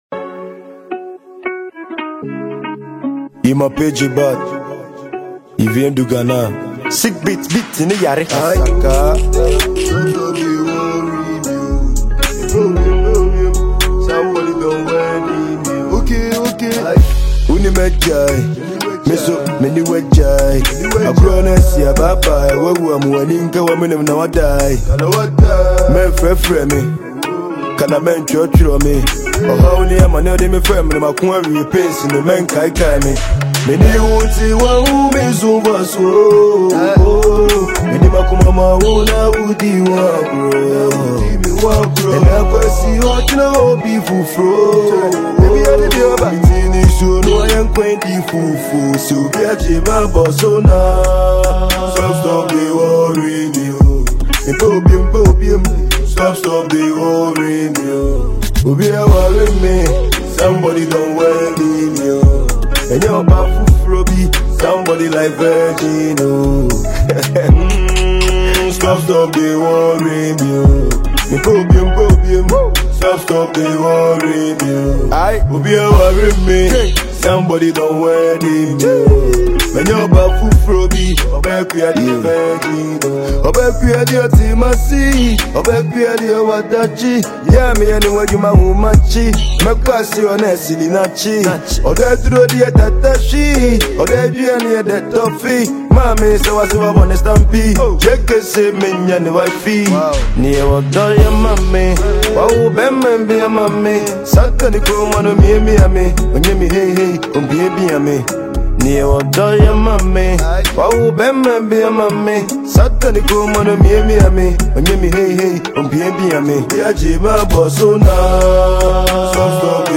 Asakaa style, blending local slang with a global drill sound